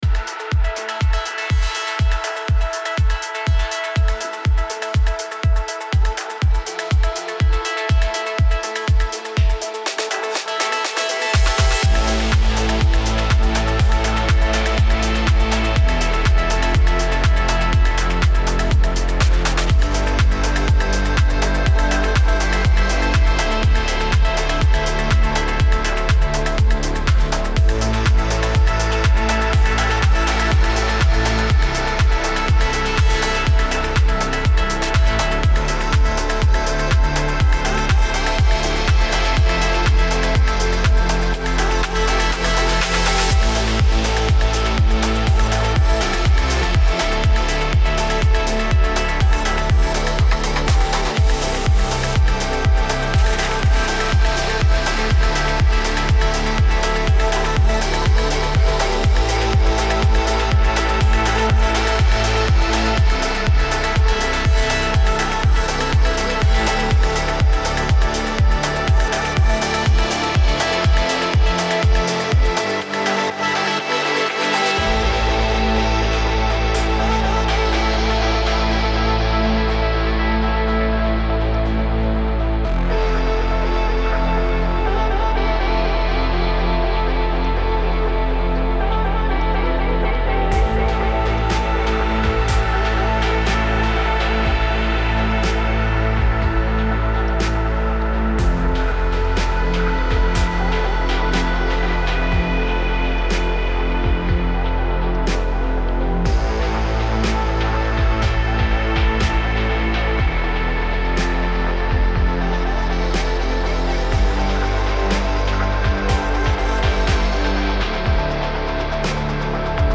ملودیک هاوس موسیقی بی کلام ملودیک هاوس